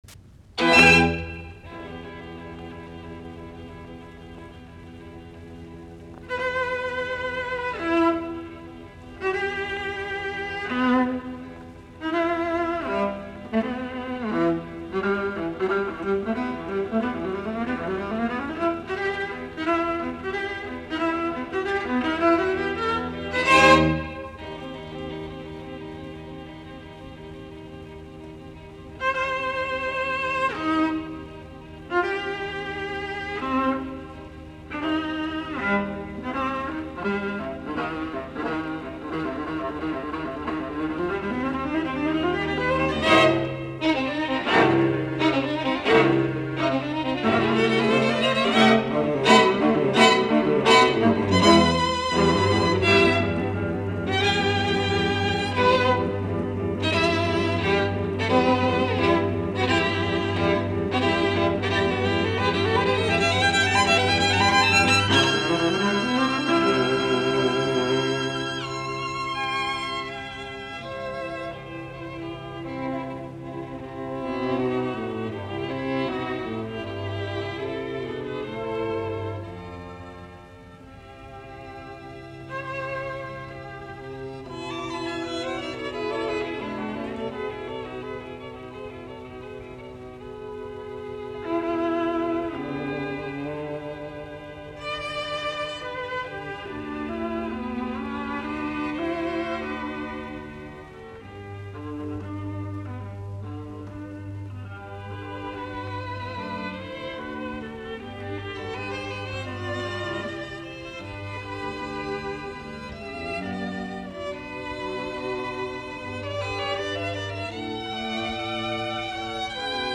Kvartetot, jouset, nro 1, e-molli
1. Allegro vivo appassionato
Soitinnus: Viulut (2), alttoviulu, sello.